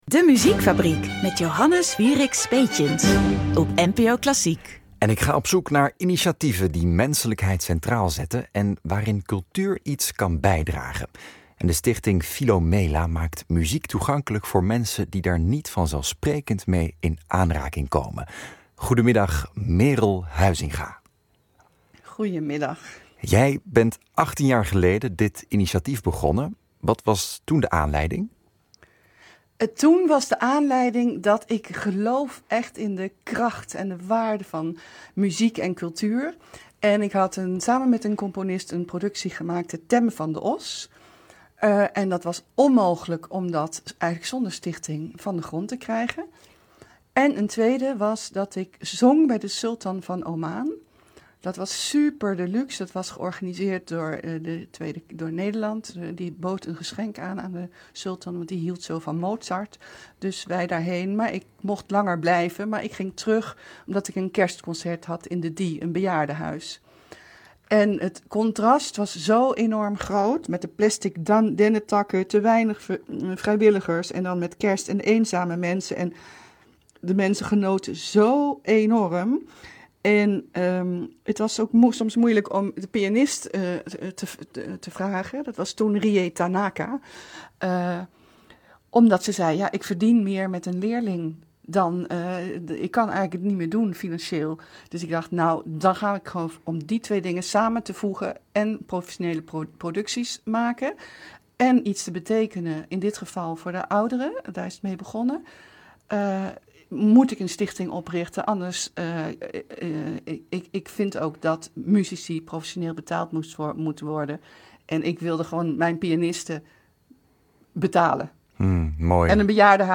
Radio-interview – De Muziekfabriek (NPO Klassiek)